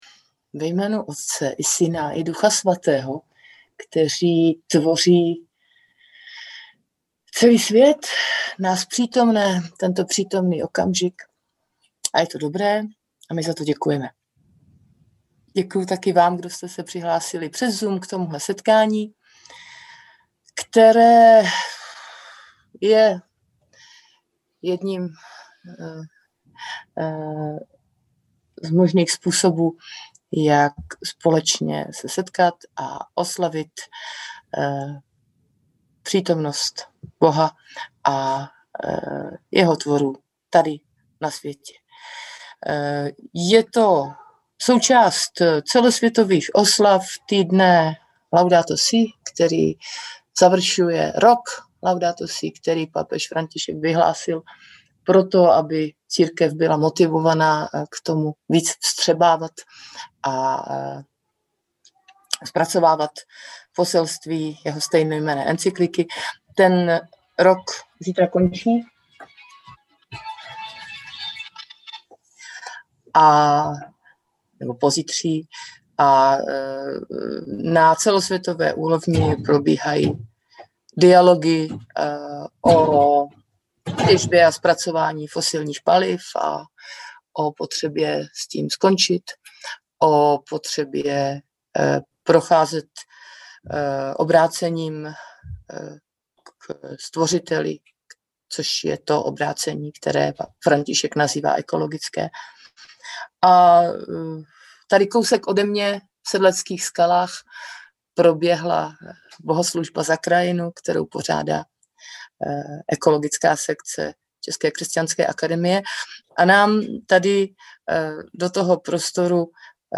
online přednášku